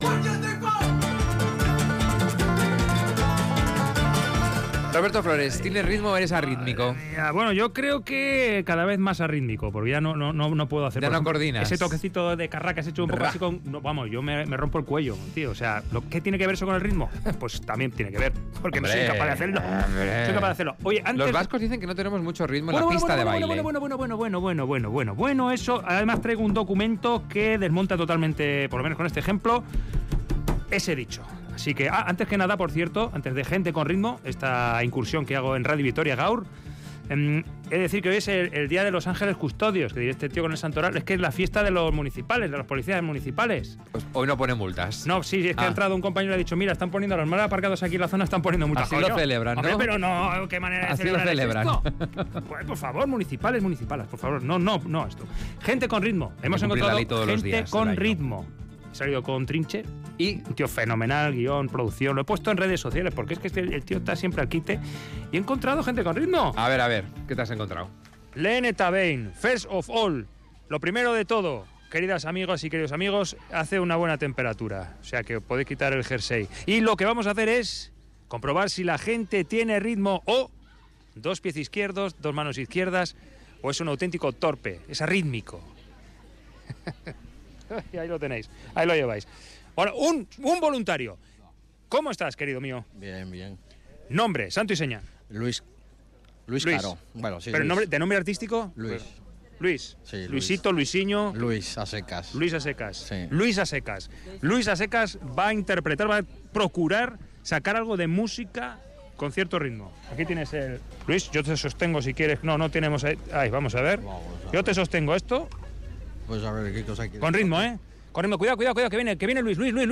Audio: Con un tambor de juguete y una paellera liliputiense la gente es capaz de demostrar que el compás corre por sus venas.